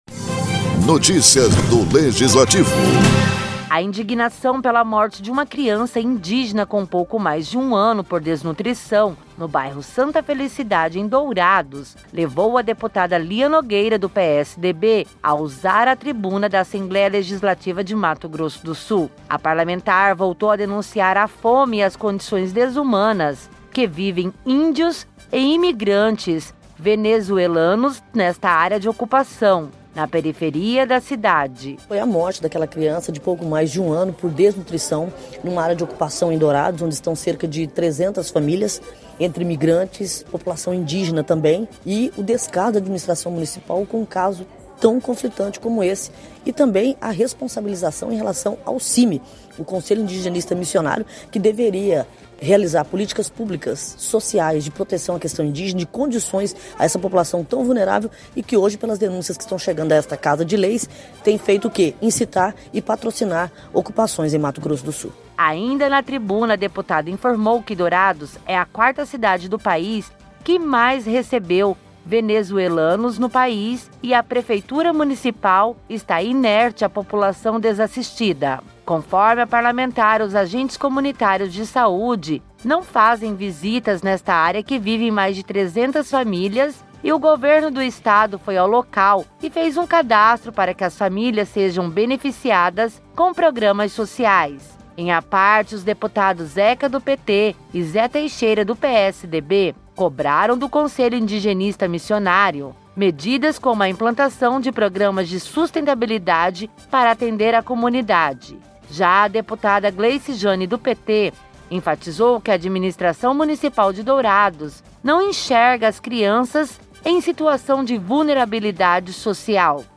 Durante a sessão ordinária a deputada estadual Lia Nogueira, do PSDB, lamentou a morte por desnutrição de uma criança indígena, com pouco mais de um ano, no Bairro Santa Felicidade, em Dourados.